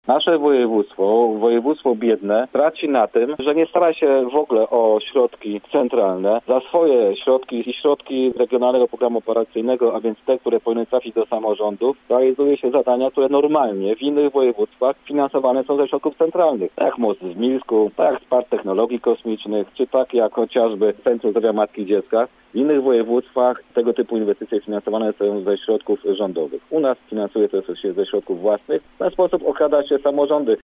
Ł. Mejza jako poseł – komentuje K. Kaliszuk – Radio Zielona Góra